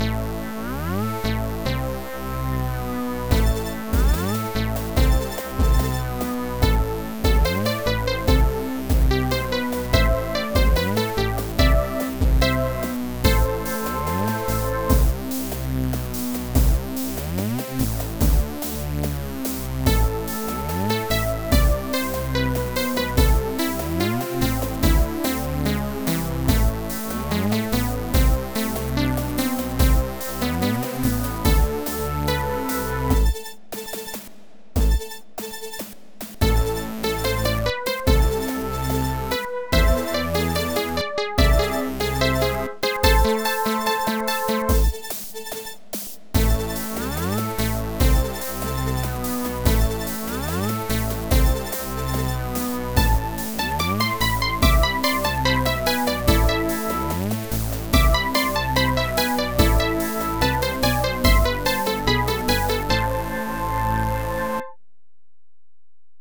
This piece makes use exclusively of the TripleOscillator plugin in LMMS.